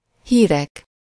Ääntäminen
IPA : /n(j)uːz/ US : IPA : [njuːz]